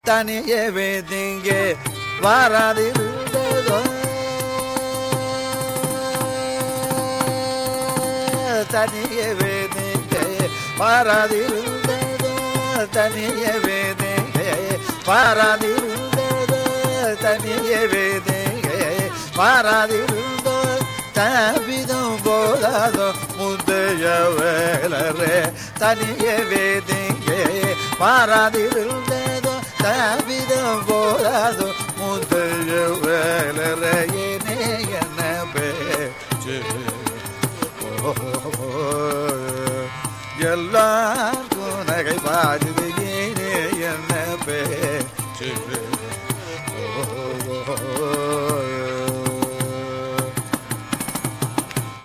Rāga Śahāna
anupallavi